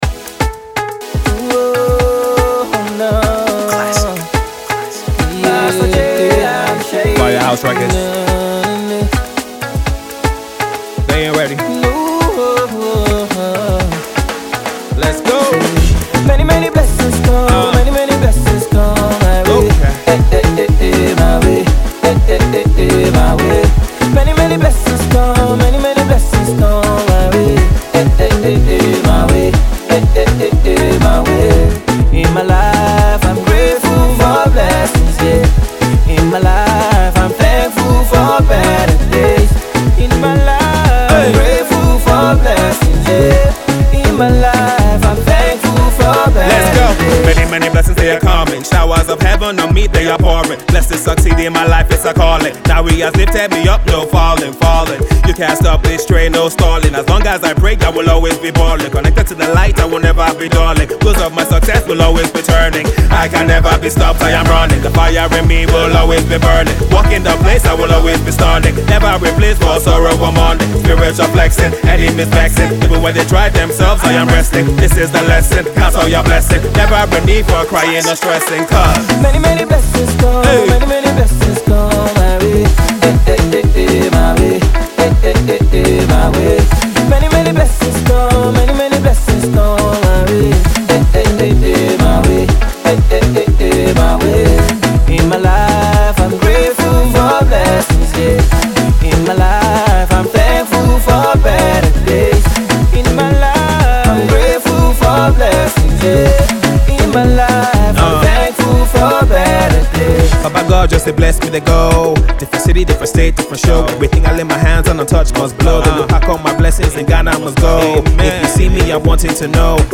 gospel single
strong vocals
an Afro beat rap fusion that should get anyone moving